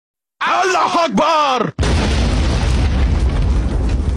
Allah Akbar meme sound, often used in exaggerated or comedic clips for memes and soundboard moments.